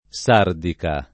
Sardica [ S# rdika ]